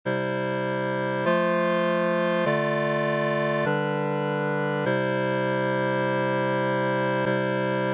It is also possible, although difficult, to simply sing the ii chord out of tune, but this kind of out-of-tune-ness will starkly stand out of the progression in a bad way, especially when the other chords are resonantly in tune, and is probably an even worse solution than simply allowing the pitch drifting to happen:
I-IV-ii-V64-I Badly Corrected ii
i-iv-ii-v64-i-badly-corrected-ii.mp3